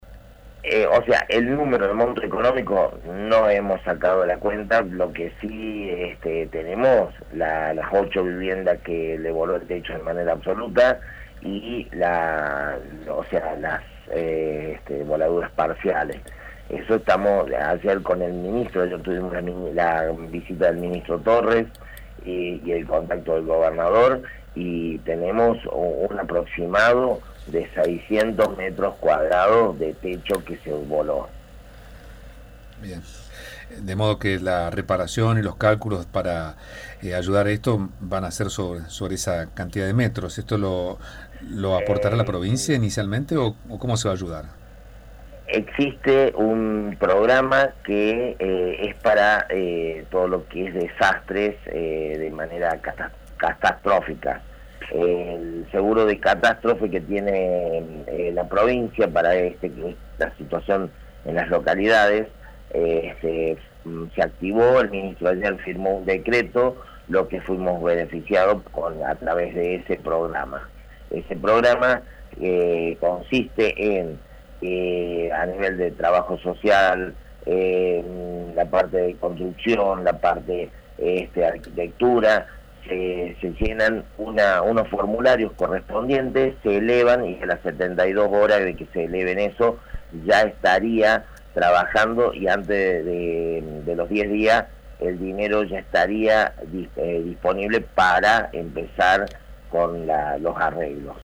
El intendente Ezequiel Moiso comentó detalles de las pérdidas producidas en la localidad.